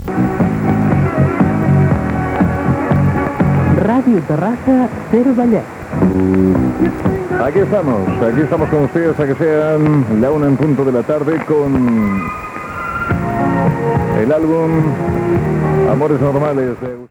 Indicatiu com Ràdio Terrassa-SER Vallès i presentació d'un tema musical.